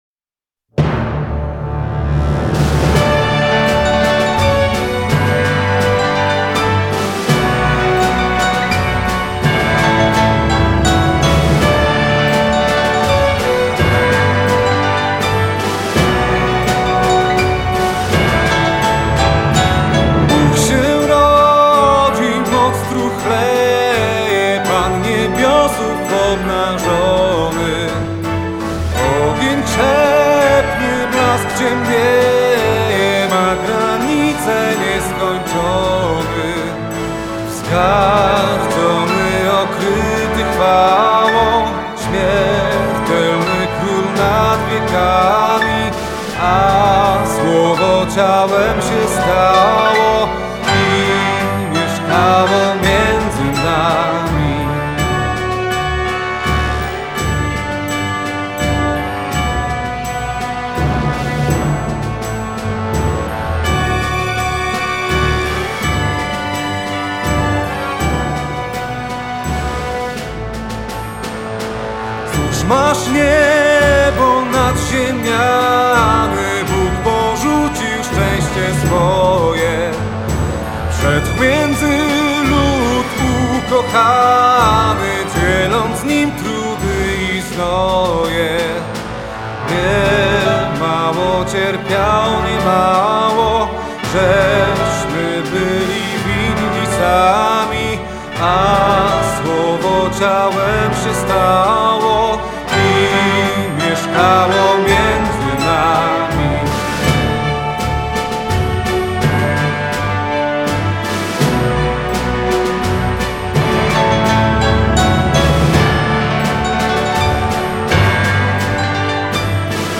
Kolędy (mp3)
Kolędy najpiękniej brzmią w tradycyjnych aranżacjach, w jakich od pokoleń śpiewamy je w naszych polskich domach w noc wigilijną.
Utwory zaaranżowane i zaśpiewane są w sposób rytmiczny i pełen melodii, bez nadmiernych interpretacji, tak aby każdy mógł je dośpiewać przy wigilijnym stole.